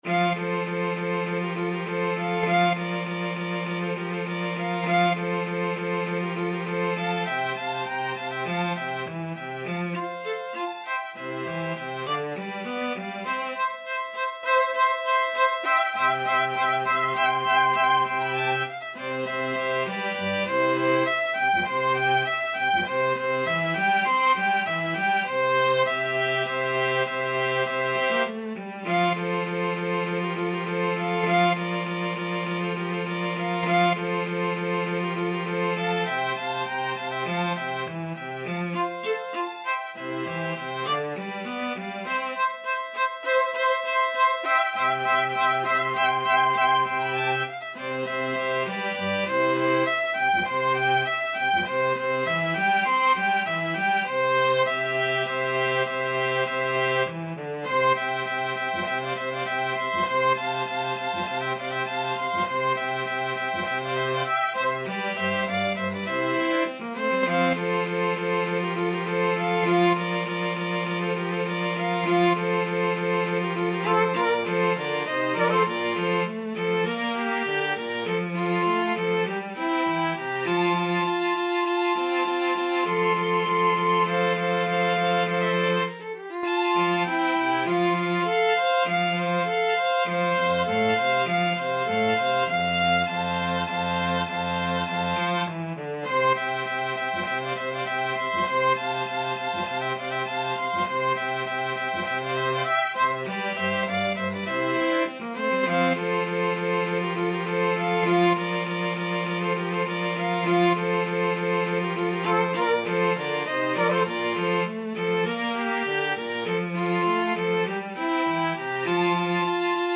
Free Sheet music for String Quartet
Violin 1Violin 2ViolaCello
~ = 100 Allegretto
F major (Sounding Pitch) (View more F major Music for String Quartet )
2/4 (View more 2/4 Music)
Classical (View more Classical String Quartet Music)
l_mozart_sleigh_ride_STRQ.mp3